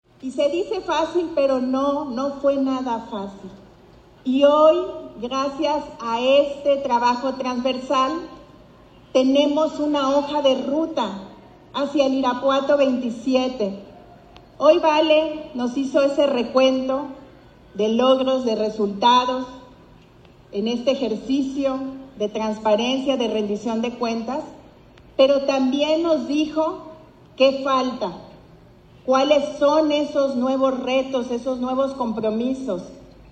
AudioBoletines
Lorena Alfaro, Presidenta Municipal
Valeria Alfaro, Presienta del DIF Municipal